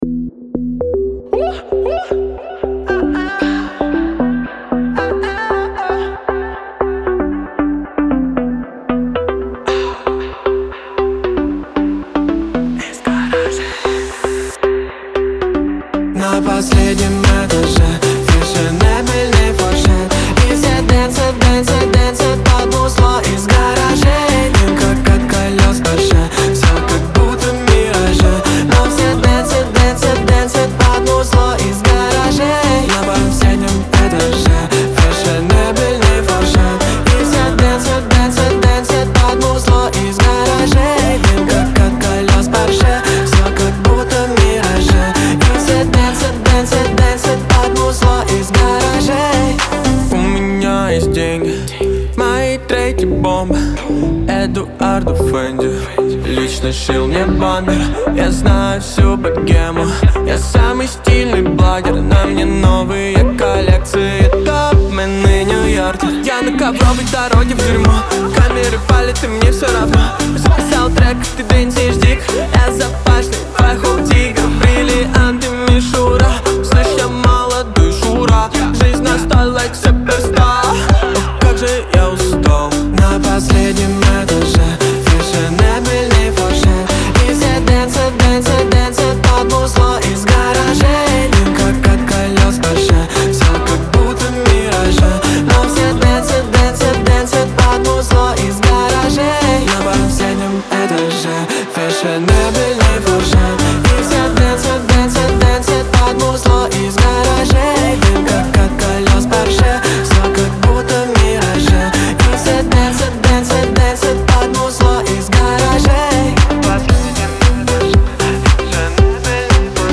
хип-хоп с элементами рэпа